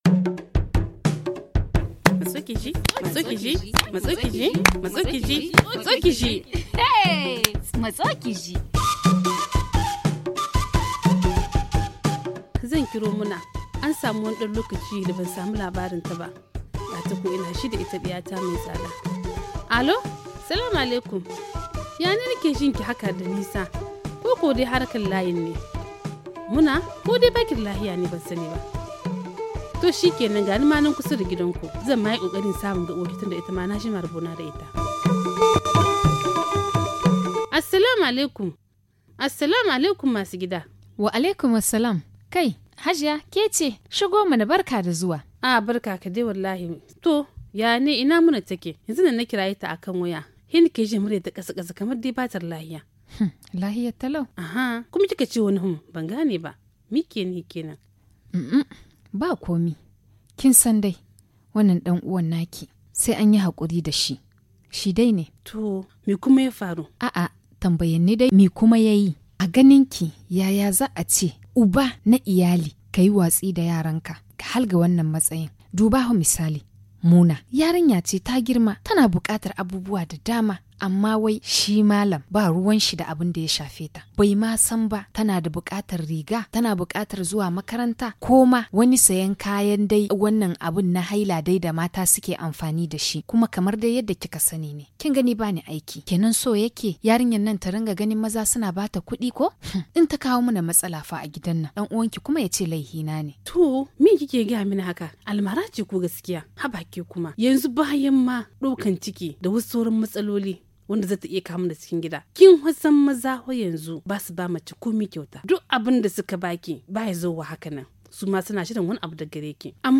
Écoutons cette discussion entre ces deux amies.